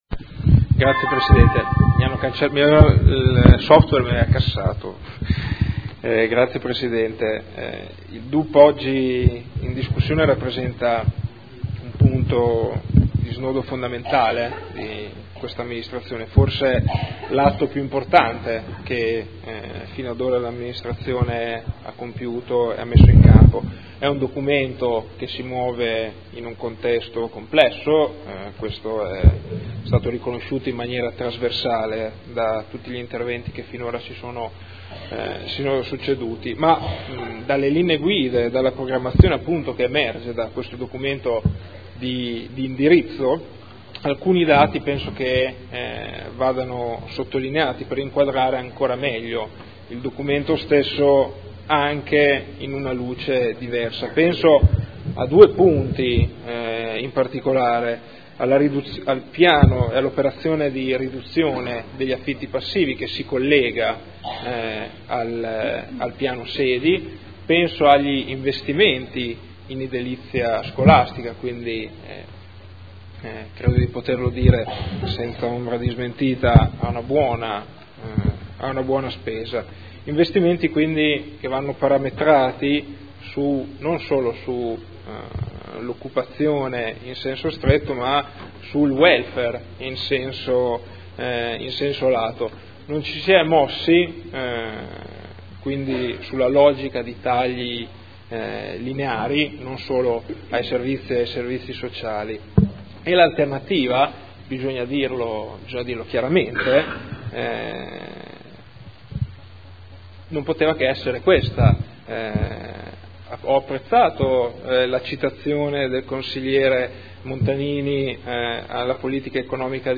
Documento Unico di Programmazione 2015/2019 – Sezione strategica. Dibattito
Audio Consiglio Comunale